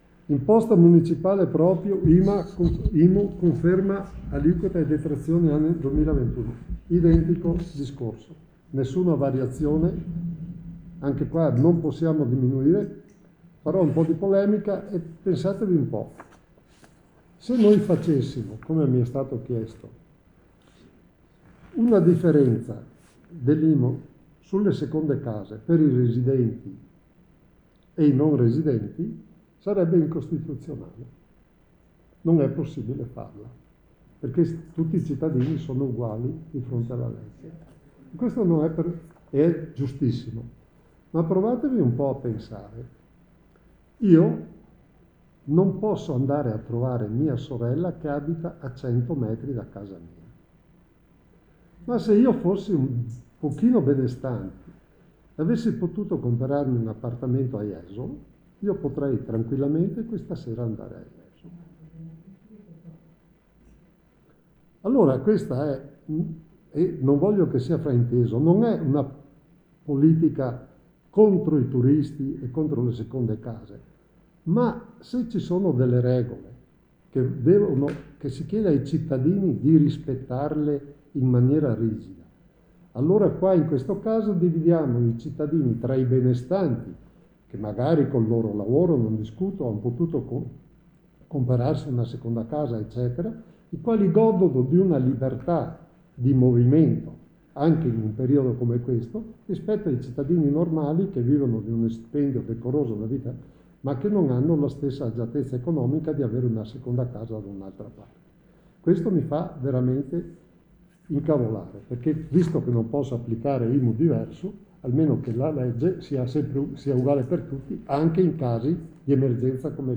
Per il primo cittadino l’occasione per dire la sua opinione sul tema degli spostamenti verso la seconda casa. L’INTERVENTO DEL SINDACO FLAVIO COLCERGNAN